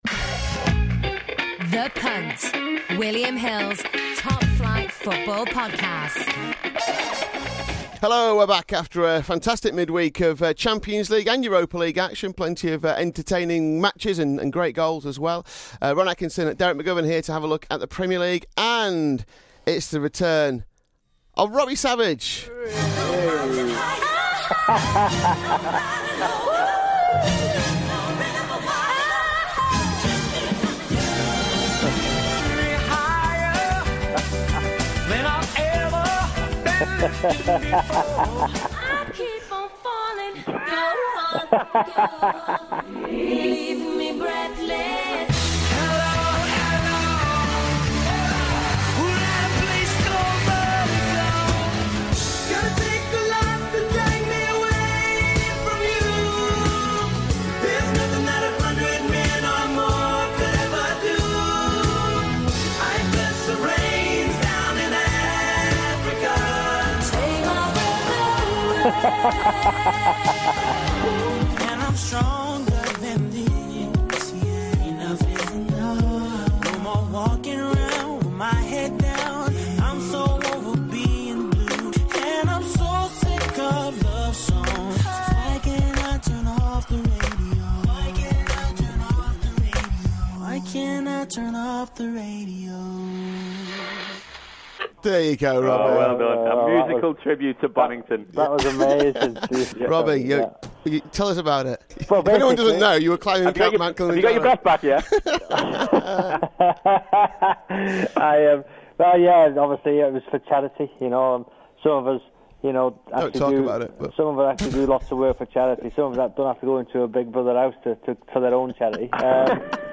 The three of them go through every Premier League match with bets in them all.